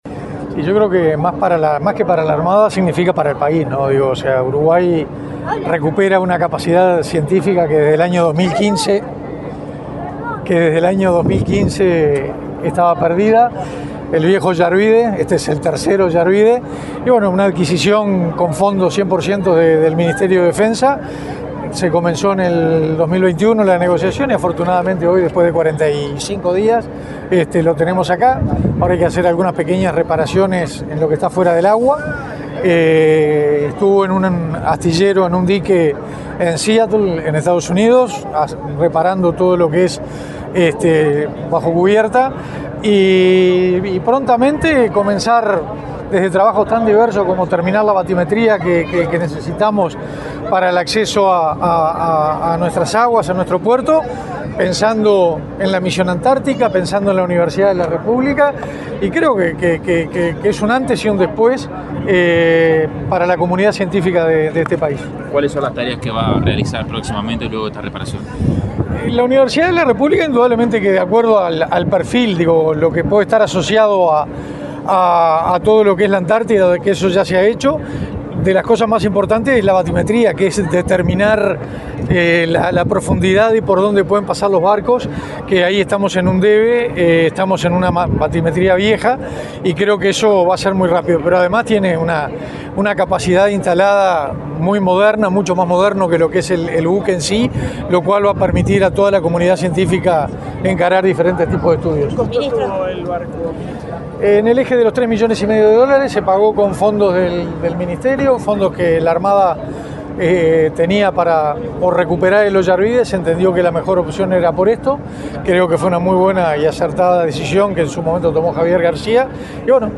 Declaraciones del ministro de Defensa, Armando Castaingdebat
Declaraciones del ministro de Defensa, Armando Castaingdebat 29/11/2024 Compartir Facebook X Copiar enlace WhatsApp LinkedIn El ministro de Defensa, Armando Castaingdebat, dialogó con Comunicación Presidencial, este viernes 29 en el puerto de Montevideo, luego de la ceremonia de recepción e incorporación del buque científico ROU 22 Oyarvide.